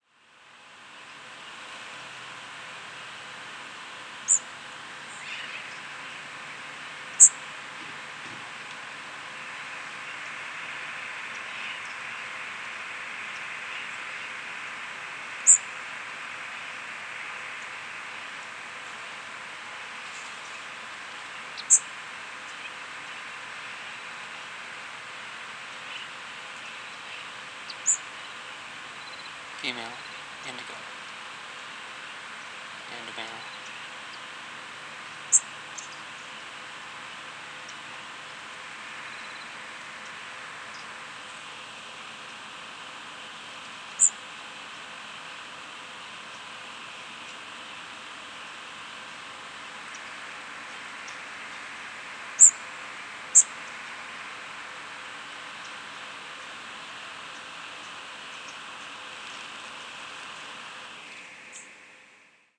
Indigo Bunting diurnal flight calls
Perched female and male alternating calls (begins with female). Sooty Terns and Palm and Hooded Warbler chip notes in the background.